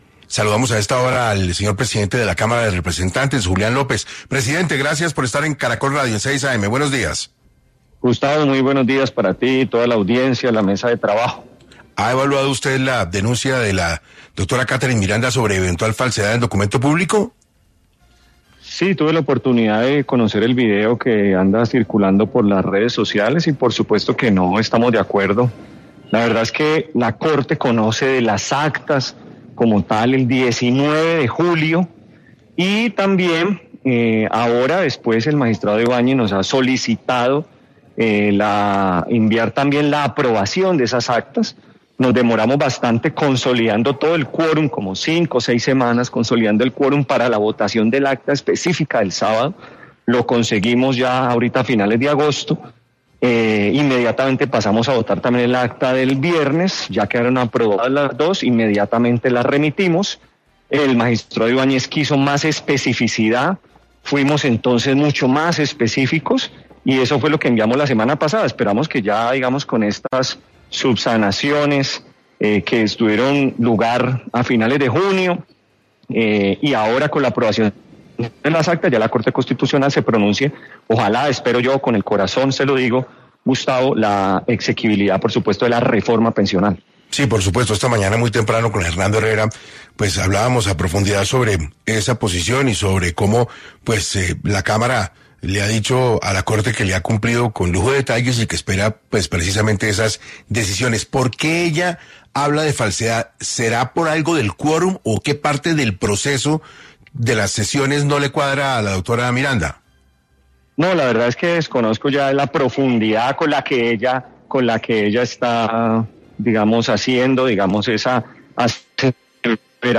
El presidente de la Cámara de Representantes habló en 6AM de las diferentes reformas del Gobierno que se están adelantando